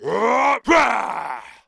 Sound / sound / monster / thiefboss3 / attack_2.wav
attack_2.wav